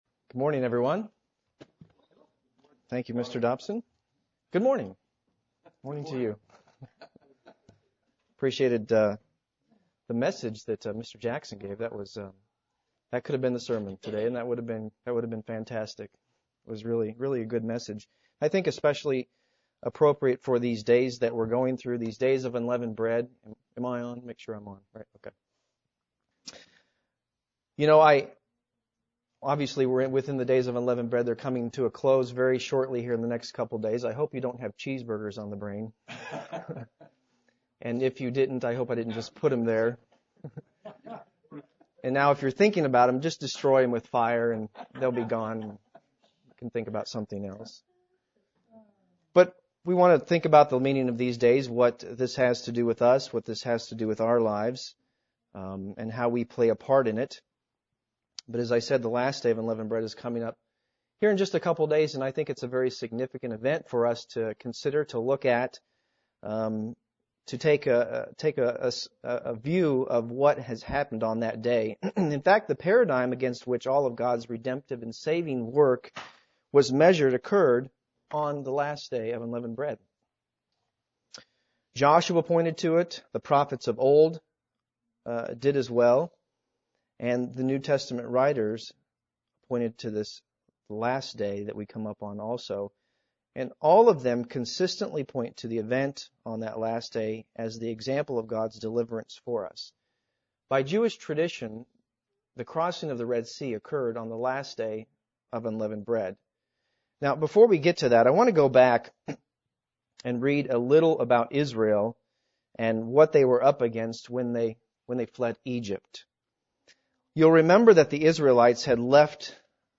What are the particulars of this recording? Given in Columbia - Fulton, MO